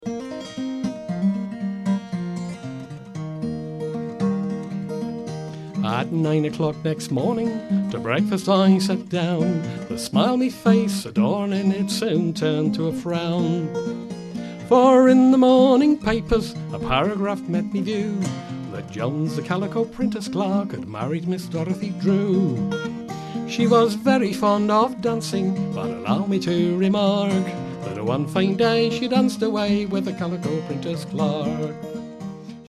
Ashington Folk Club - 01 February 2007